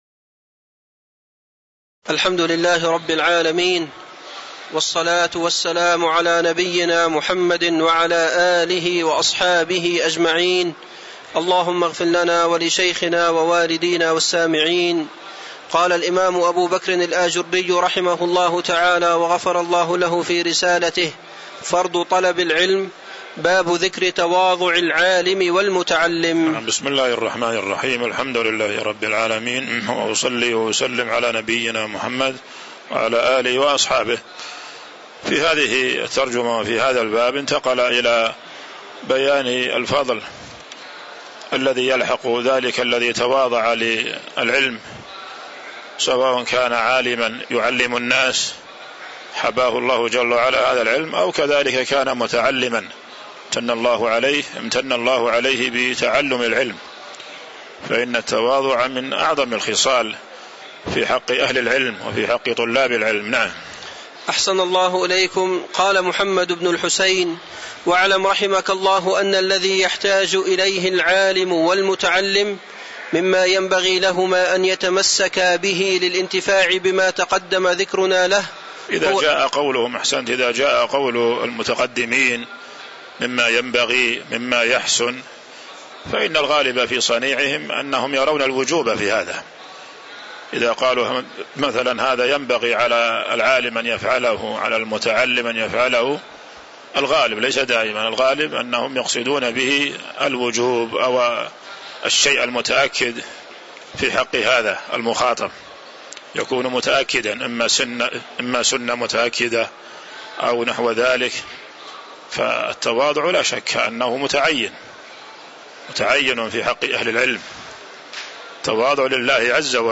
تاريخ النشر ١٨ ربيع الثاني ١٤٤٥ هـ المكان: المسجد النبوي الشيخ